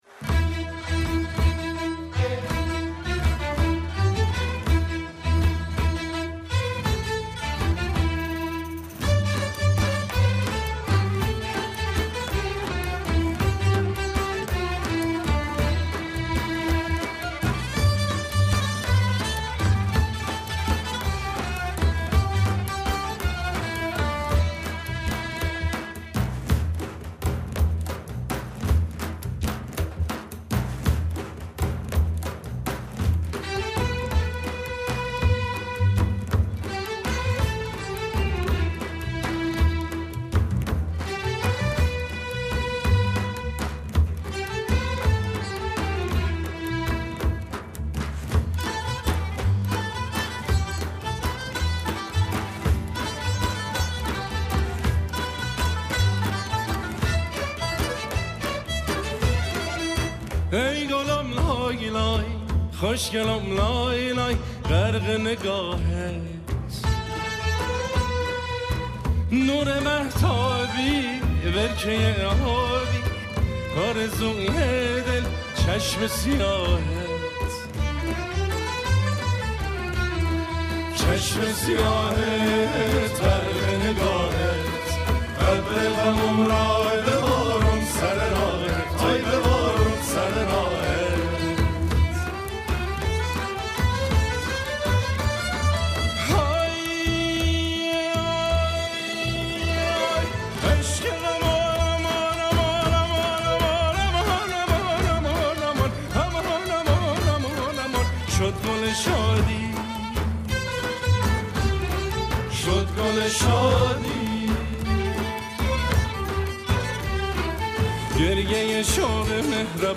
براساس تم محلی